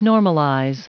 Prononciation du mot : normalize
normalize.wav